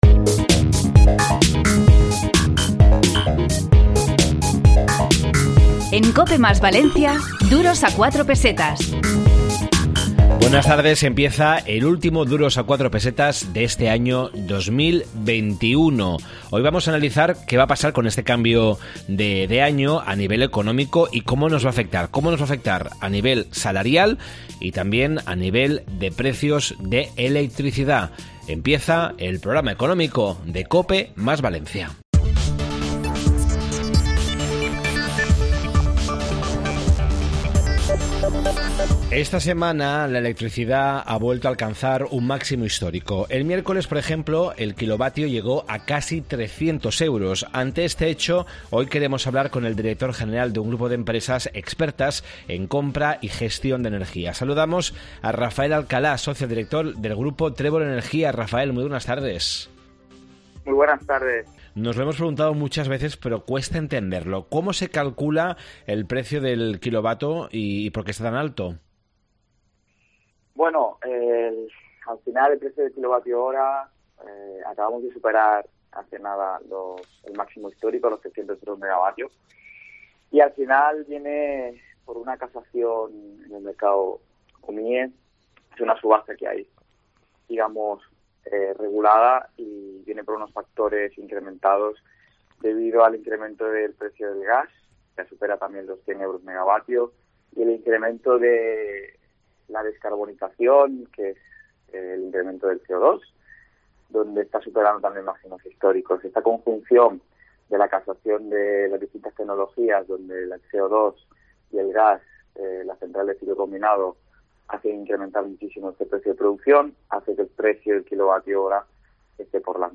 Esta semana en Duros a 4 Pesetas de COPE + Valencia, en el 92.0 de la FM, hemos preparado un programa dedicado a la gestión de energía, la inflación y los accidentes cardiovasculares.